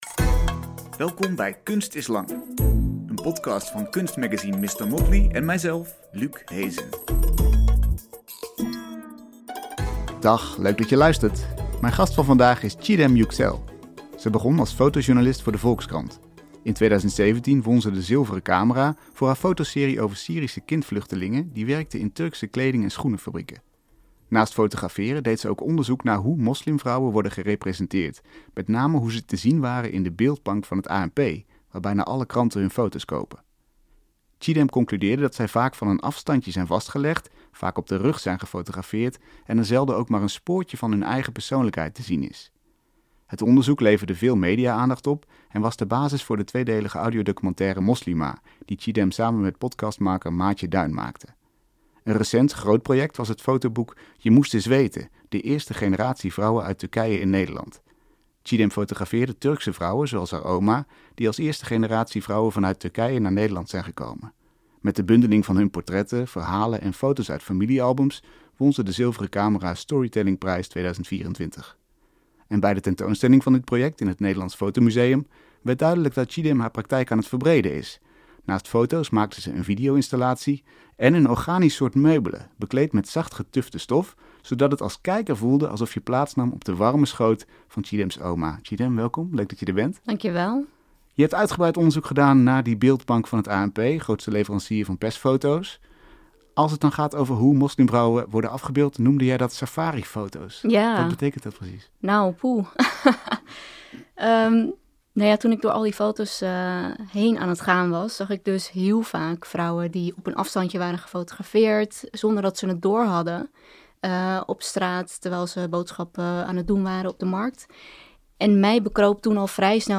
Je hoort elke week een uitgebreid gesprek met een kunstenaar over de belangrijkste thema's van diens oeuvre, inspiratiebronnen en drijfveren. Mét diepgang, maar zonder ingewikkeld jargon.